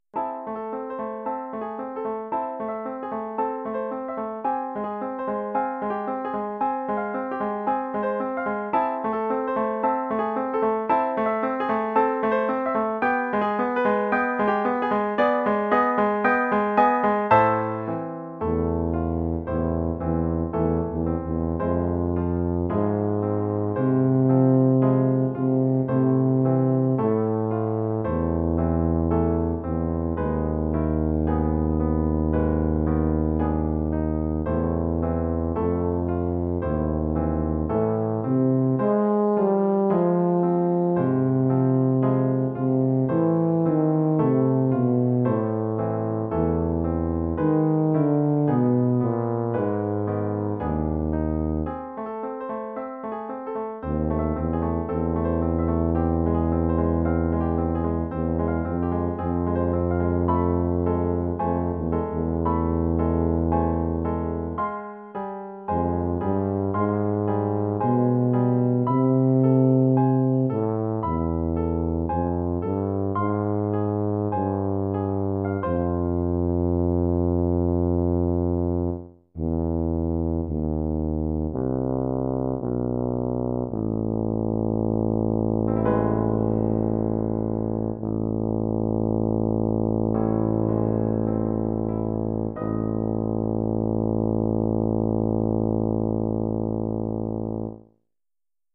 Formule instrumentale : Saxhorn basse/Tuba et piano
Oeuvre pour saxhorn basse / euphonium /
tuba et piano.